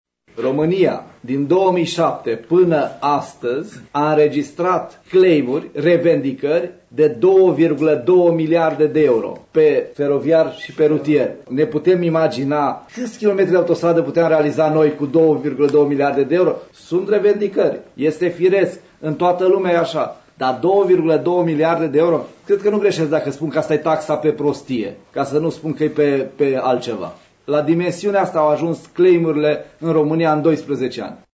Suma reprezintă revendicările antreprenorilor, a declarat astăzi, la Ungheni, Ministrul Transporturilor, Infrastructurii și Comunicațiilor, Lucian Bode, aflat într-o vizită de lucru pe șantierele celor două loturi ale Autostrăzii Transilvania. Ministrul a catalogat pretențiile constuctorilor drept o ”taxă pe prostie” plătită de statul român.